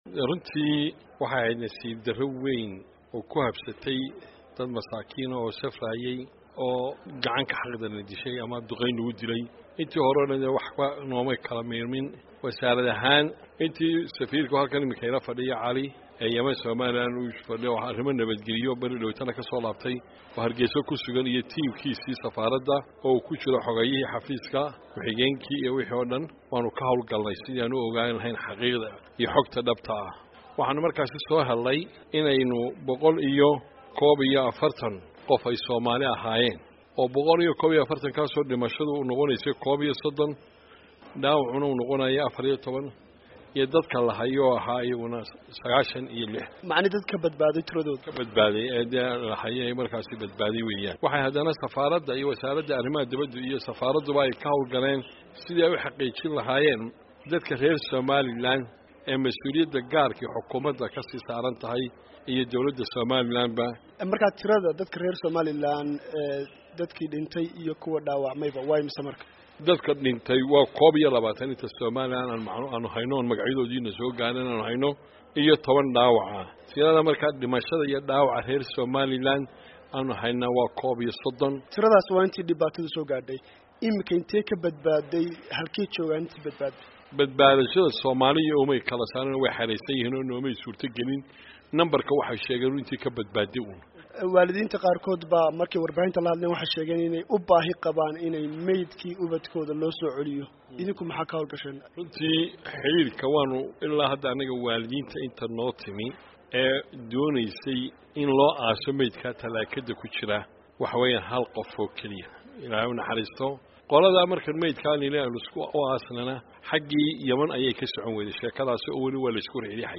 Wareysi Dadkii ku Dhintay Duqeynti Yemen